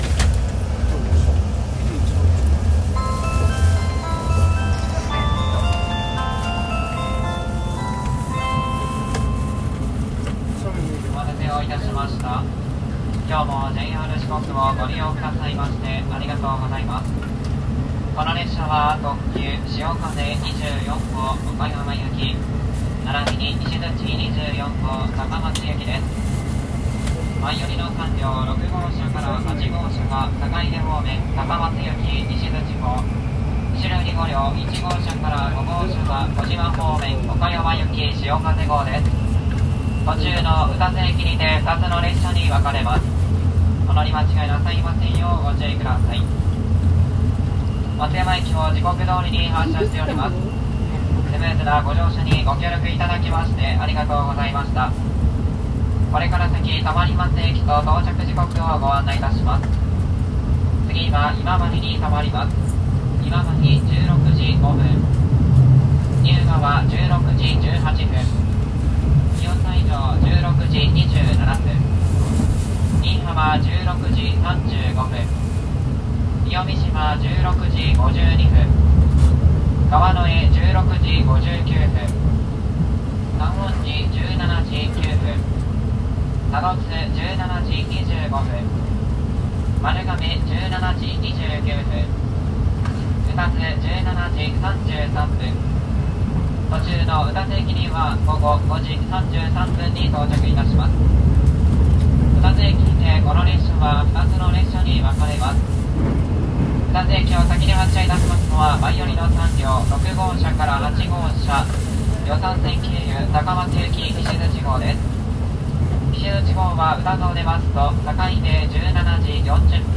147 いしづち号車内音
2025年6月、いしづち24（しおかぜ24号と併結）号の予讃線・松山発車後に録音しました。 音質・音量ともに良いとは言えませんが、備忘録として掲載します。
ご注意 Wi-Fi環境推奨です 録音者の手元の雑音はご容赦ください 音量（突然の大きな音）にご注意ください 松山駅発車後 いしづち24号車内音 ファイル名：250604ishizuchi24.mp3 容量：約7.9MB 時間：8分49秒 （新規タブで開きます） 御礼 お聴きいただきまして、ありがとうございます。